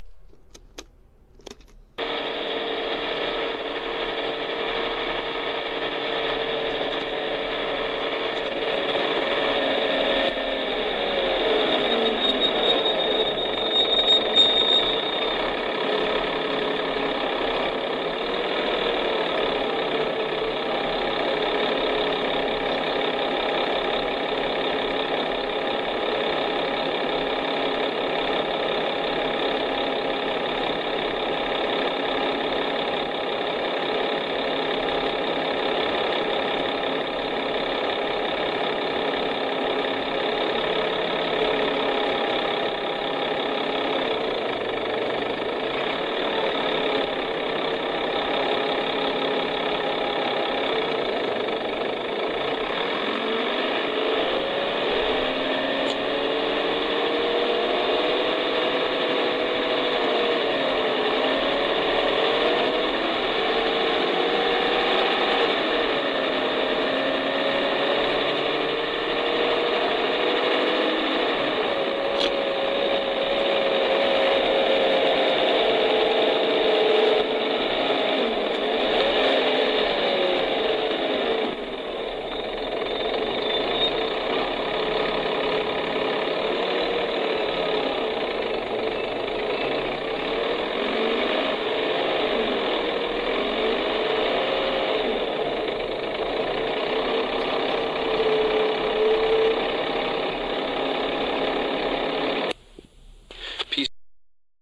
Dennis Trident 12m